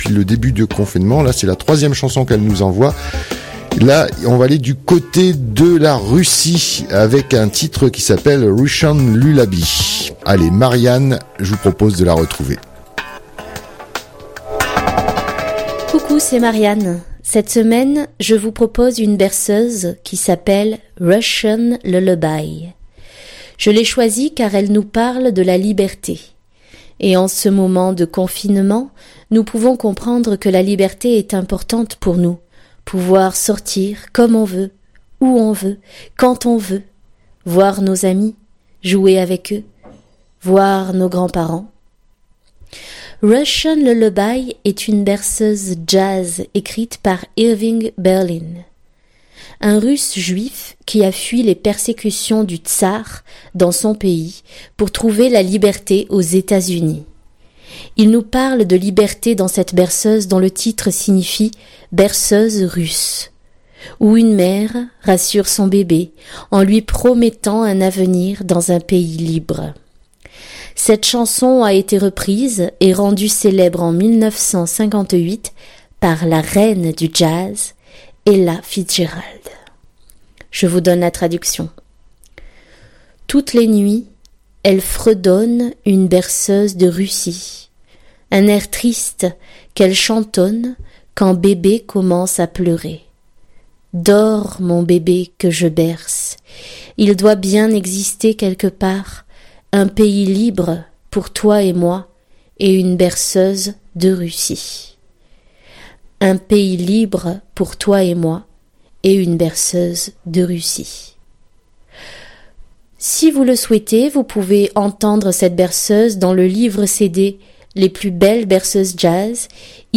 Elle offre chaque semaine une chanson du monde à destination d'un jeune public qu'elle enregistre chez elle, rien que pour vous les jeunes auditrices et auditeurs de Fréquence Mistral...
Avant de chanter, elle nous donne quelques explications sur le sens du texte et les instruments qu'elle utilise. je vous propose de découvrir cette artiste généreuse